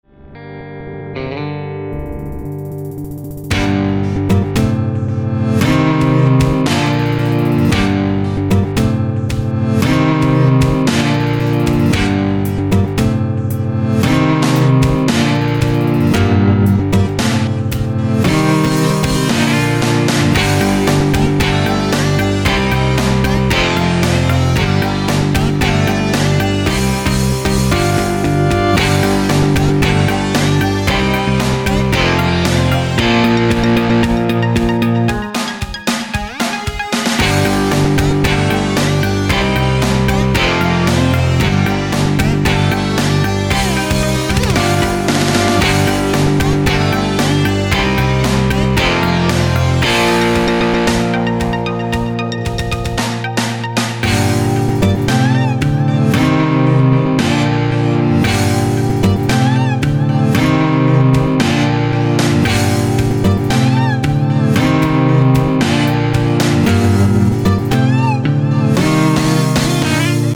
[demo] инструментал
Здесь только наброск, без сведения.